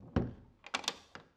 SFX_Door_Close_02.wav